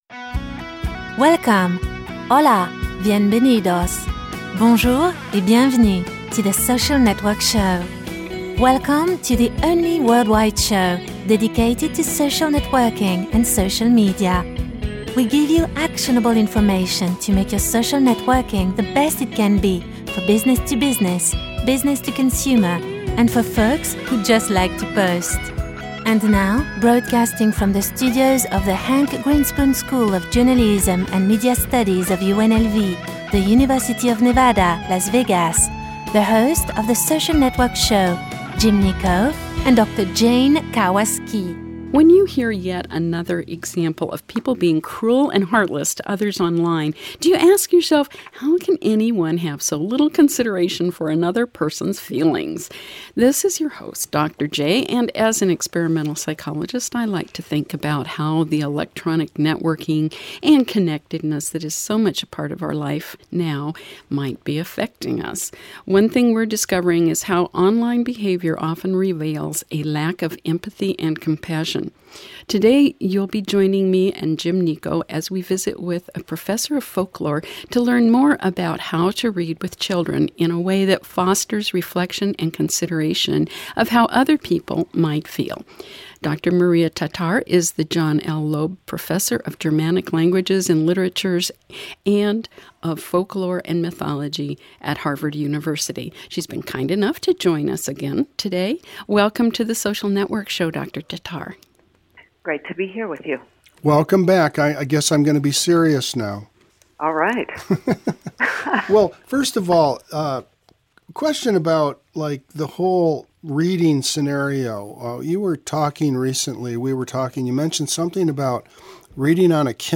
Dr. Maria Tatar, a professor of folklore, children's literature and German cultural studies talks about the importance of reading to children.